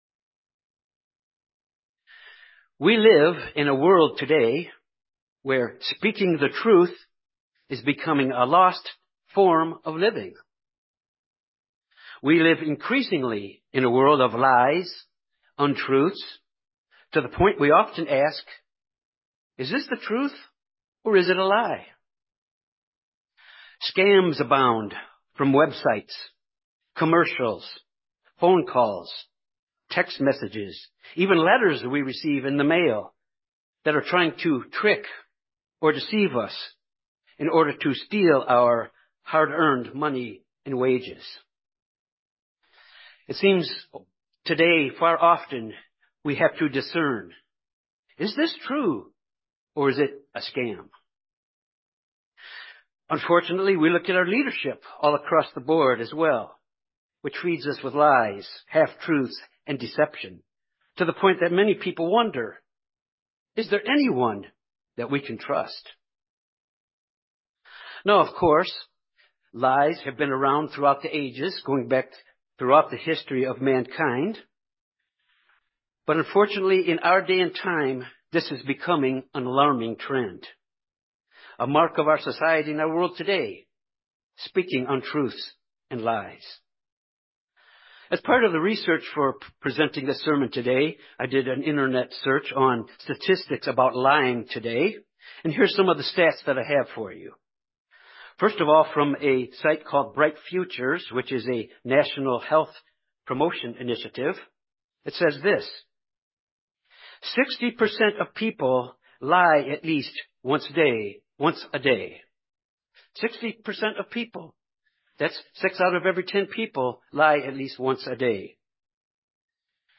This sermon looks at truth and the importance of truth to God.